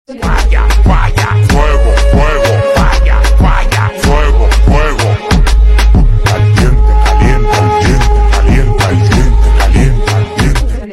Los audios subliminales son sonidos especialmente mezclados con afirmaciones positivas para que sean percibidas por el subconsciente directamente. El audio subliminal puede ser una canción o un sonido relajante mezclado con afirmaciones positivas que se desea que lleguen a la mente subconsciente.
Primero escribió todas las afirmaciones para después ponerles la voz en la aplicación de "Voz de Zueira" luego de que ya están listas, en la app de "CapCut" las añado y las pongo en cámara rápida.